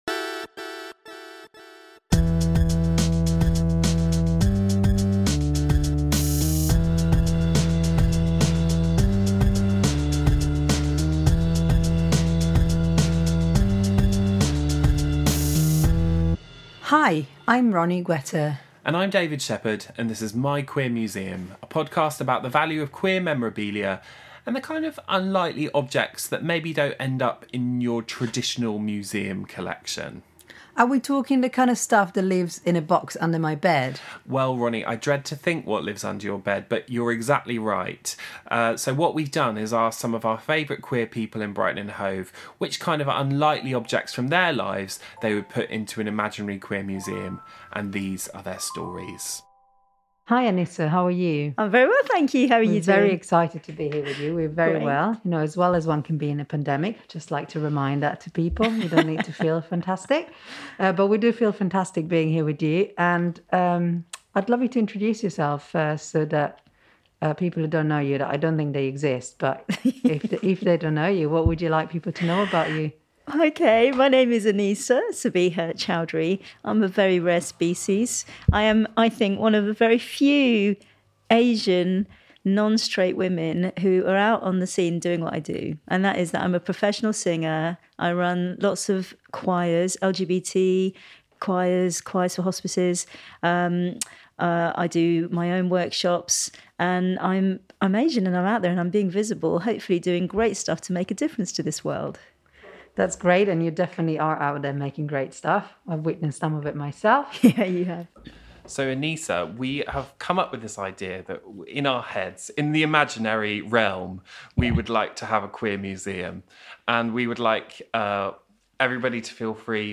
at The Spire, Brighton
Interview